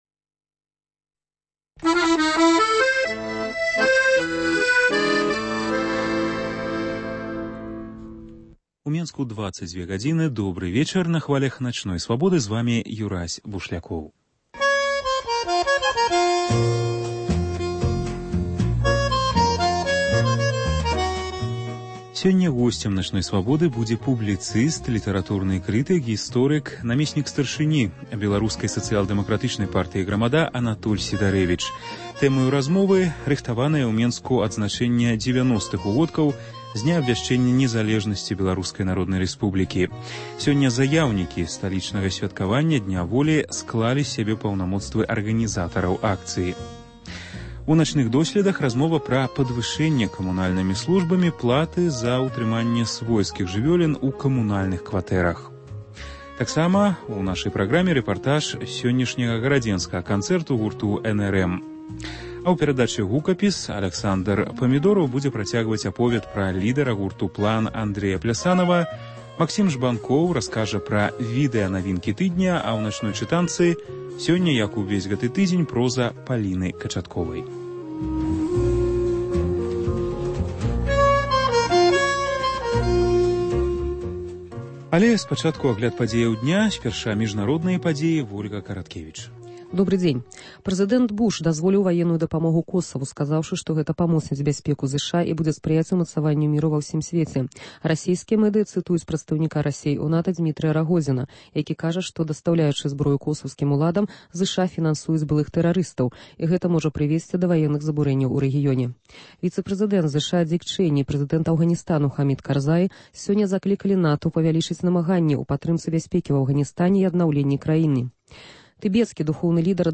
Тэмаю размовы – рыхтаванае ў Менску адзначэньне 90-х угодкаў з дня абвяшчэньня незалежнасьці Беларускай Народнай Рэспублікі. У “Начных досьледах” размова пра падвышэньне камунальнымі службамі платы за ўтрыманьне свойскіх жывёлін у камунальных кватэрах. У нашым апытаньні магілёўцы скажуць, якую жывёліну яны завялі б у сябе дома. Рэпартаж зь сёньняшняга гарадзенскага канцэрту гурту “N.R.M”.